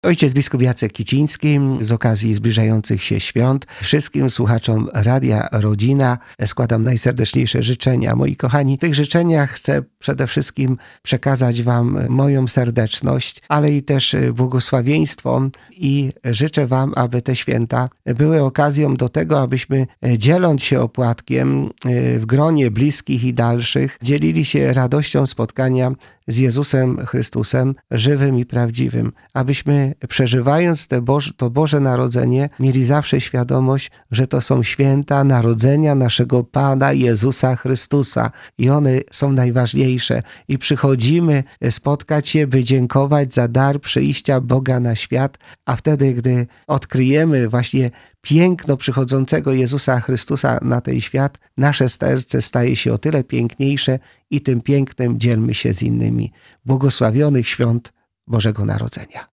Jak przeżyć ten czas i doświadczyć Bożej Miłości? – pytamy ojca biskupa Jacka Kicińskiego.
Biskup złożył także życzenia wszystkim słuchaczom Radia Rodzina.